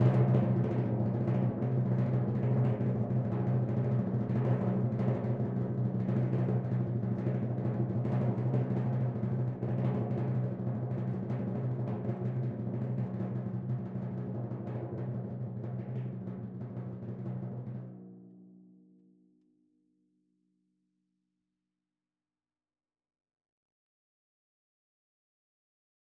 Percussion / Timpani / Rolls
Timpani2_Roll_v5_rr1_Sum.wav